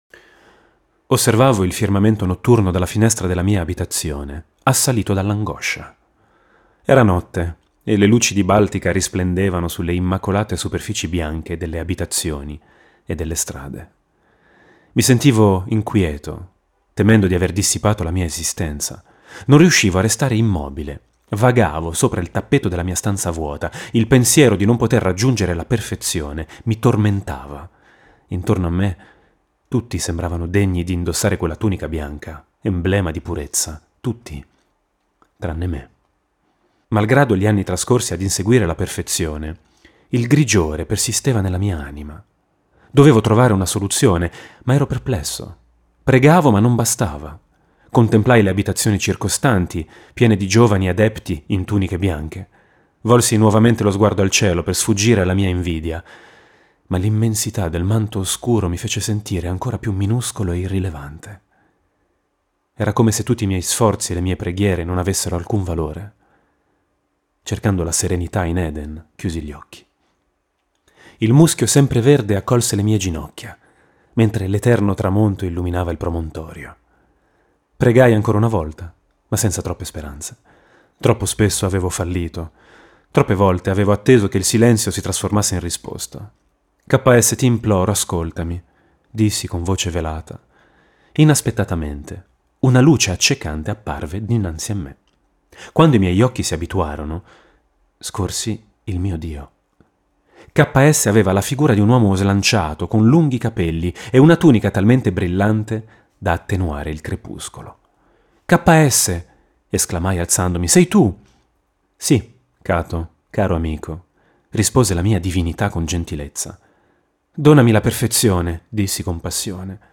La Divina Avventura - Capitolo Due - Audiolibro